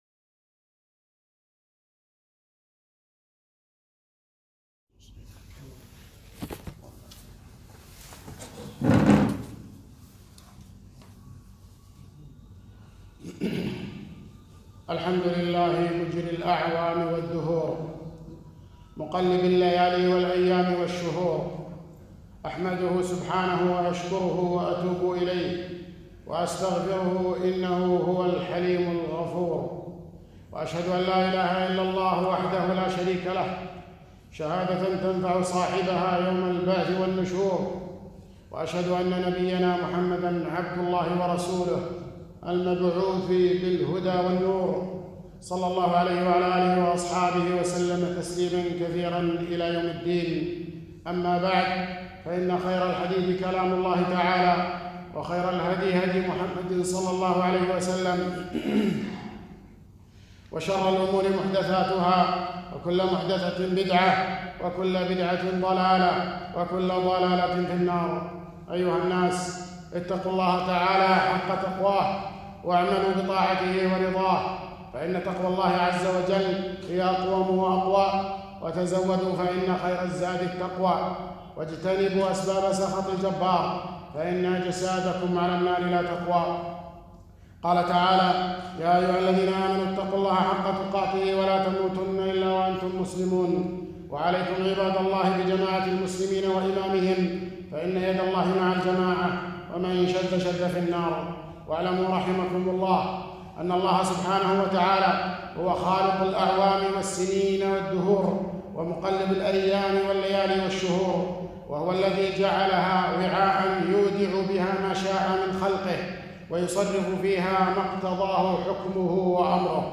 خطبة - سب الدهر وسب عام ٢٠٢٠